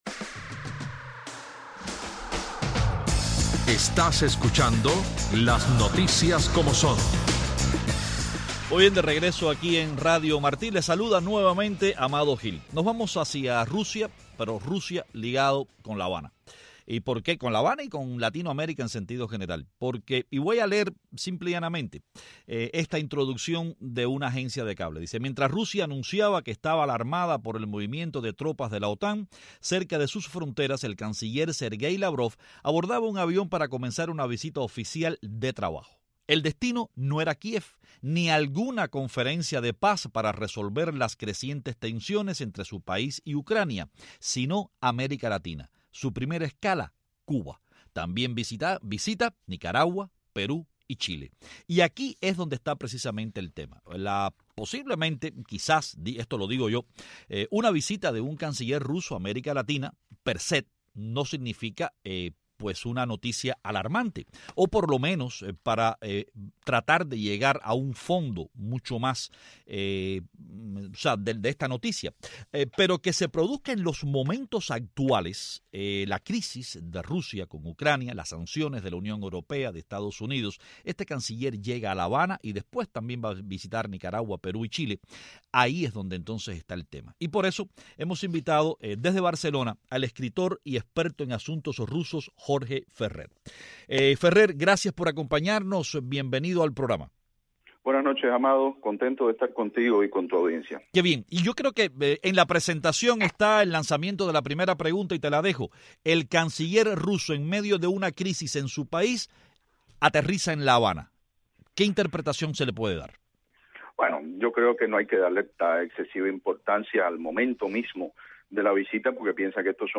Mientras crecen las tensiones entre Moscú y Occidente por la crisis en Ucrania, el ministro de Asuntos Exteriores de Rusia, Serguéi Lavrov, se encuentra en Cuba, como parte de una gira por varias naciones latinoamericanas. Desde Barcelona, nuestro invitado es el escritor y experto en asuntos rusos
Desde Madrid, nuestro invitado es Teófilo de Luis Rodríguez, diputado por Madrid del Congreso de Diputados español.